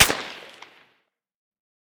heav_crack_09.ogg